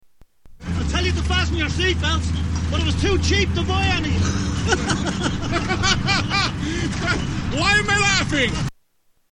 Laughing